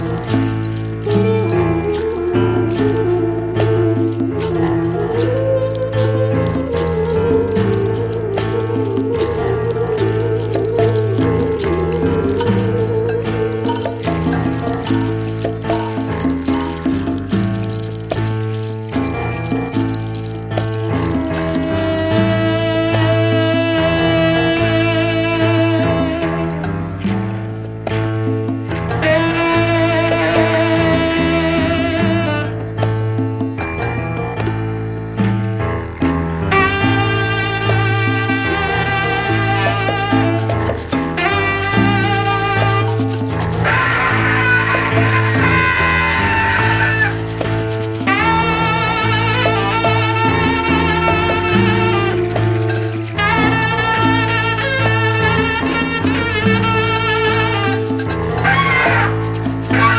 Pharoah Sandersを彷彿とさせる、生々しくタフでありながら優美なサックス。